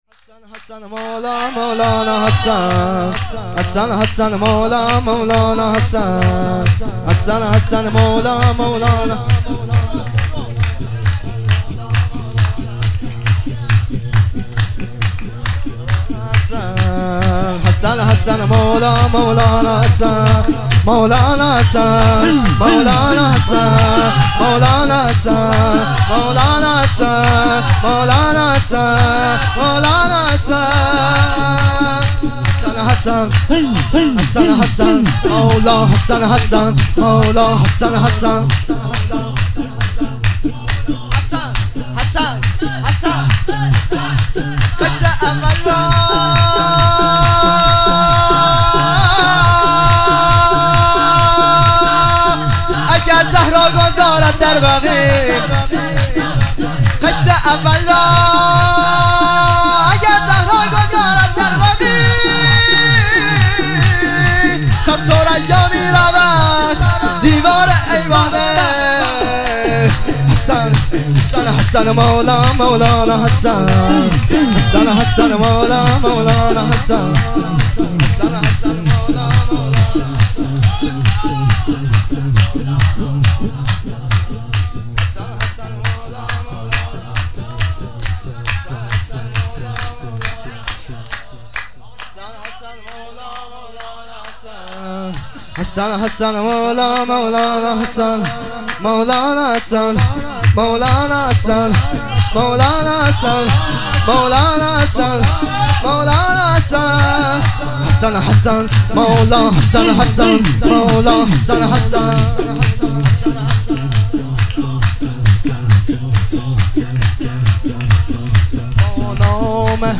جشن ولادت امام حسن عسکری علیه السلام ۱۴-۹-۹۸